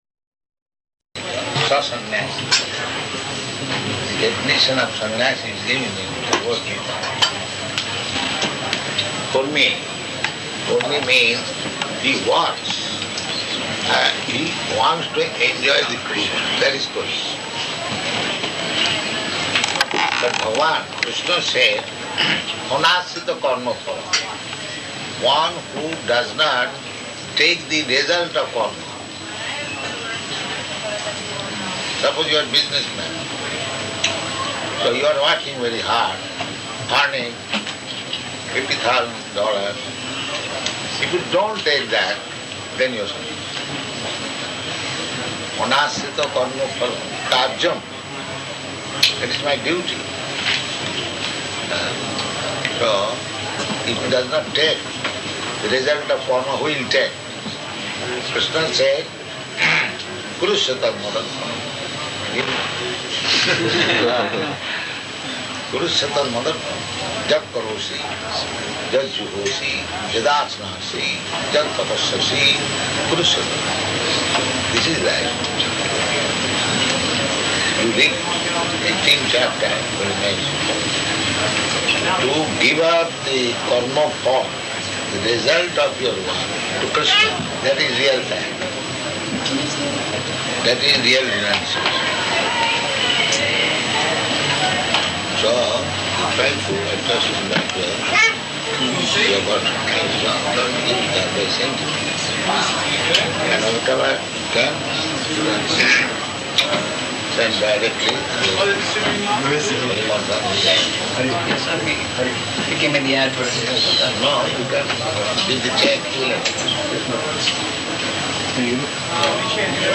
Departure Address --:-- --:-- Type: Lectures and Addresses Dated: July 25th 1974 Location: New York Audio file: 740725DP.NY.mp3 Prabhupāda: Karma karoti sa sannyāsī [ Bg. 6.1 ].